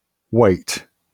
IPA/weɪt/
wymowa amerykańska?/i